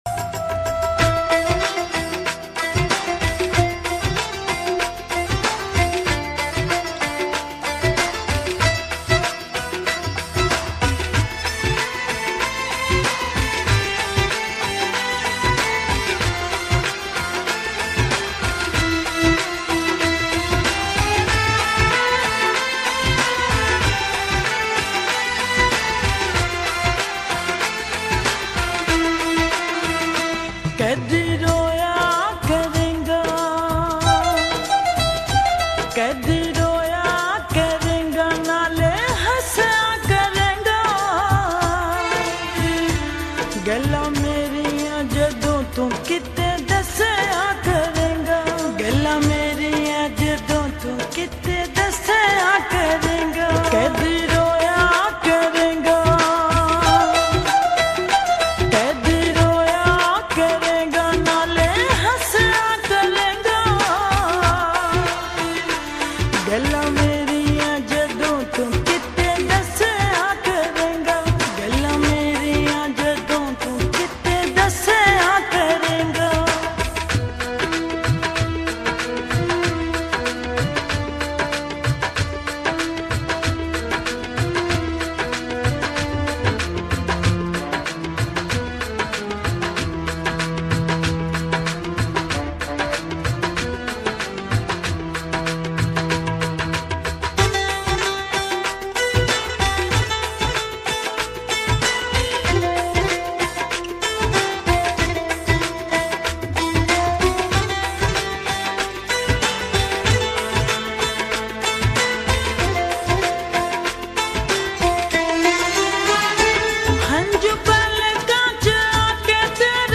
slow reverb